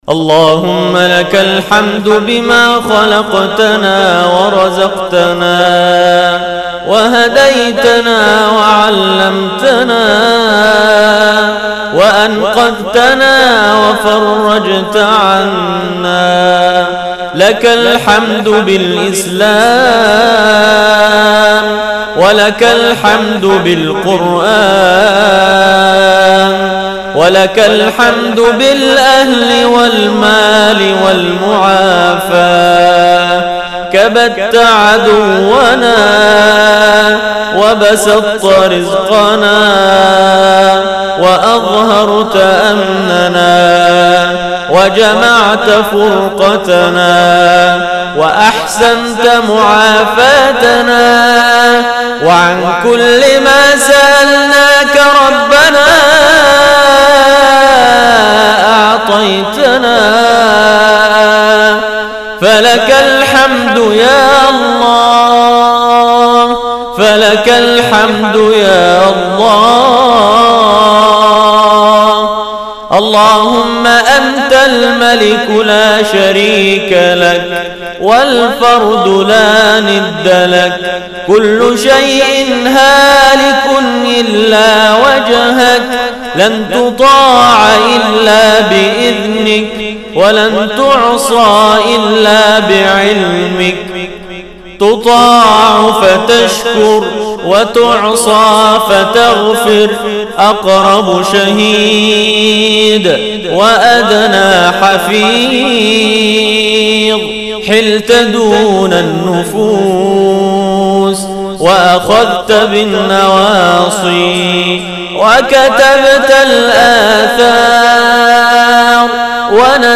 أدعية وأذكار
تسجيل لدعاء خاشع ومؤثر من ليالي رمضان 1437هـ